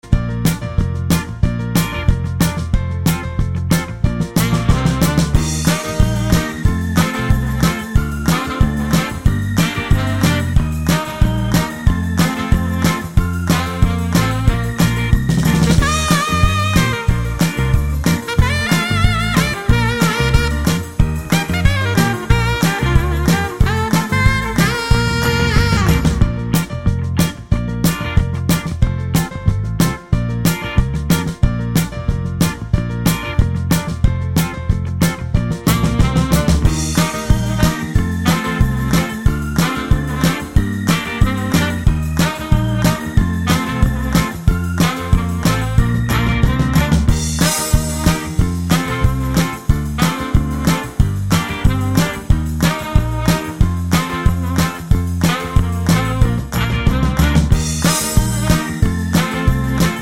Minus Drums Rock 'n' Roll 3:04 Buy £1.50